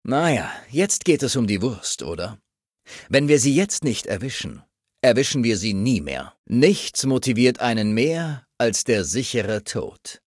Broken Steel: Audiodialoge